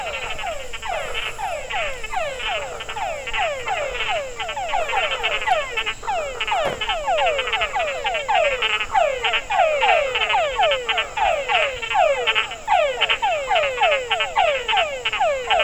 Our Airbnb was comfortable, but at nightfall, we heard this repetitive, loud noise like a video game; specifically Asteroids.
Thirty-two decibel earplugs were no obstacle for the frog’s mating calls which persisted through the night.
Listen here to a few Tungara frogs and share in the misery…
lazer_frogs_Gamboa_sample.mp3